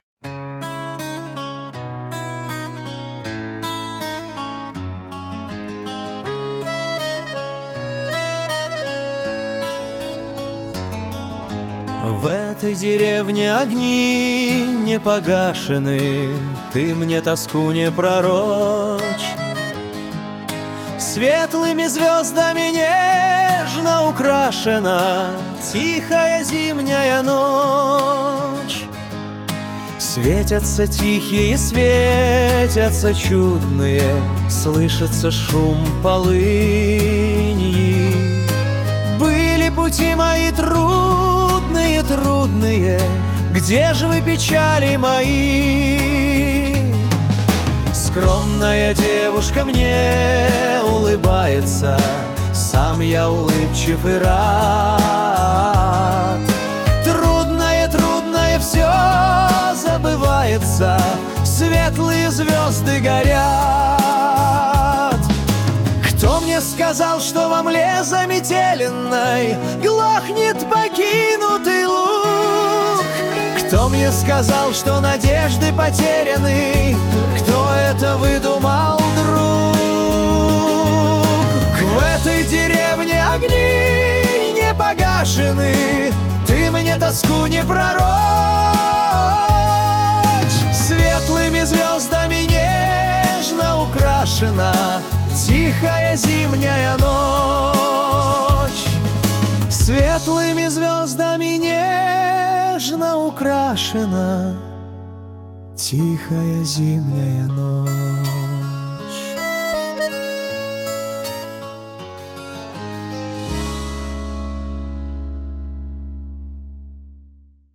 • Жанр: Детские песни
народный мотив